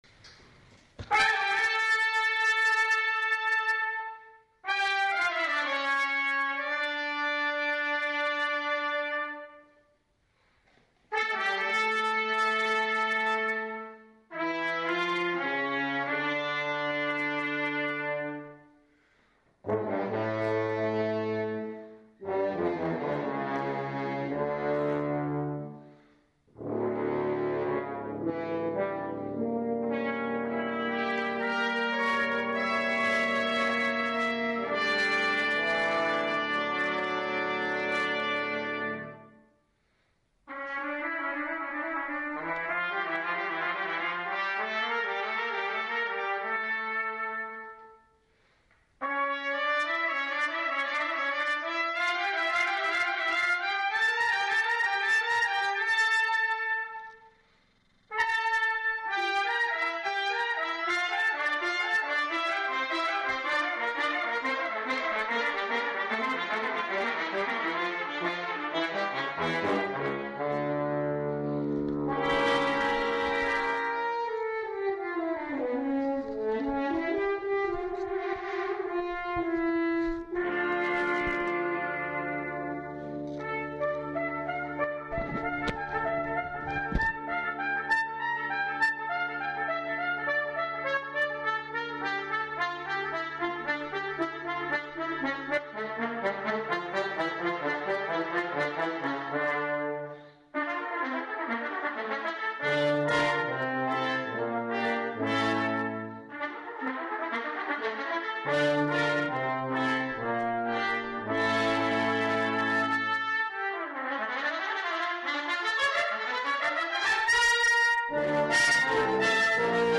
quintet (2 trumpets, horn, trombone, and tuba)
as performed at a memorial celebration
(Sorry, the recording quality is not great.)